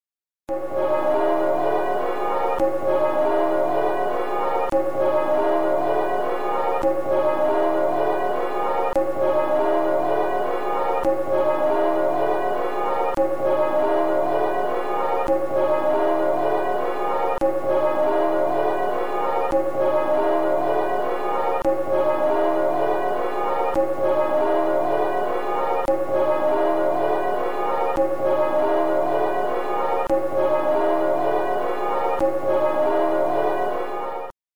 1)  Ringing Rounds in reverse ie 6 5 4 3 2 1
St Buryan Bells
st-buryan-rung-backwards.mp3